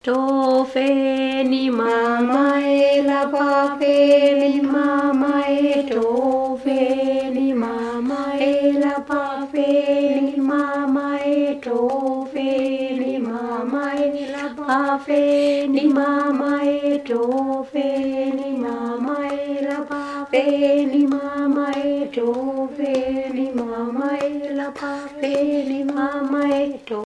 Enquête avec enregistrements sonores
Chants enfantins kanaks
Pièce musicale inédite